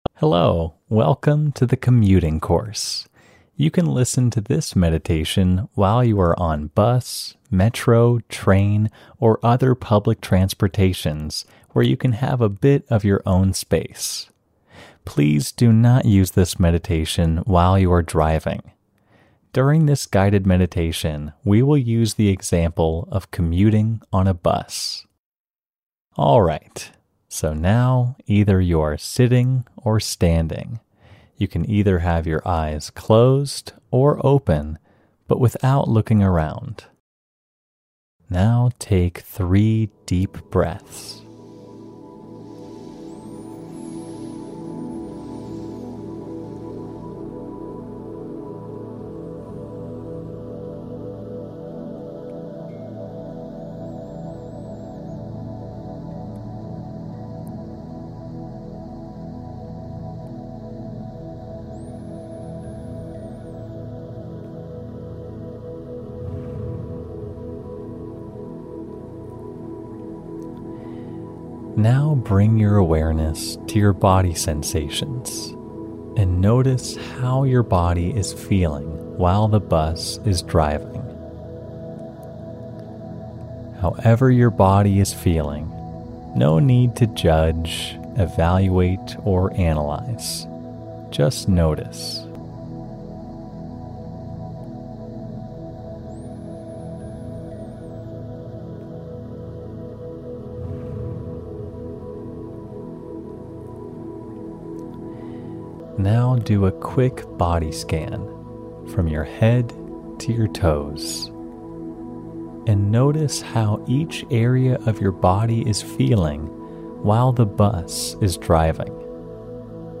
Commuting-with-music-5-minutes-female.mp3